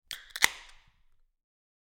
دانلود آهنگ آب 13 از افکت صوتی طبیعت و محیط
جلوه های صوتی
دانلود صدای آب 13 از ساعد نیوز با لینک مستقیم و کیفیت بالا